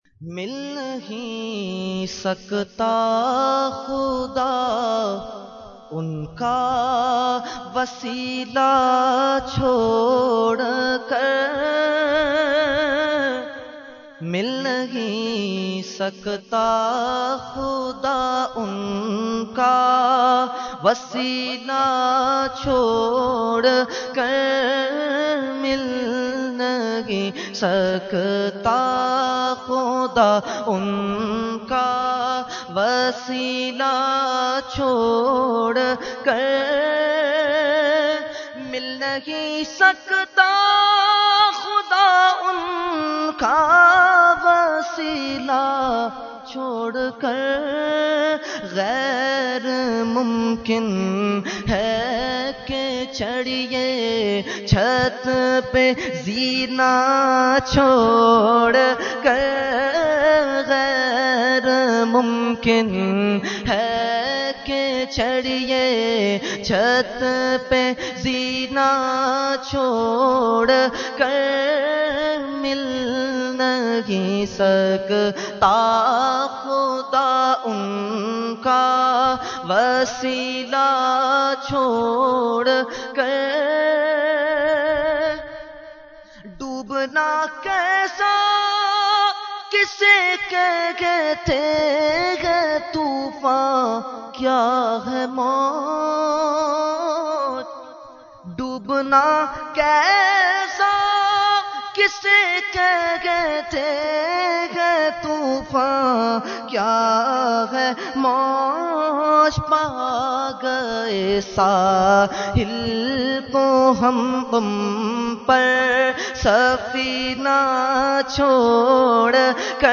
Category : Naat | Language : UrduEvent : Khatmul Quran 2014